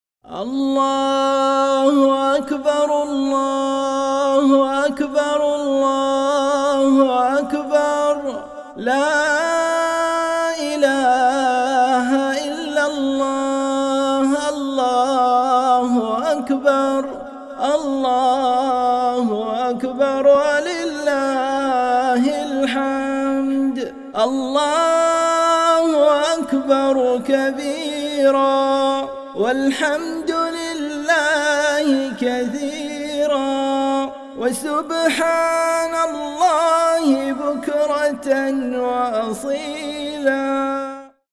الأنواع: دعاء